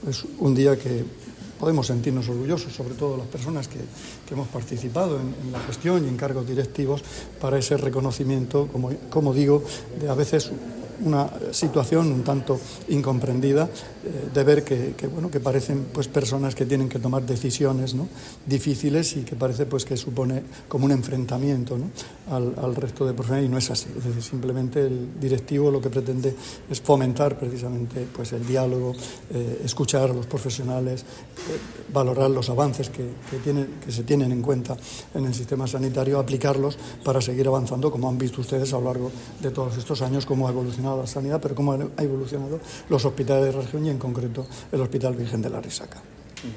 Declaraciones del consejero de Salud, Juan José Pedreño, sobre el homenaje a los equipos directivos del hospital de la Arrixaca.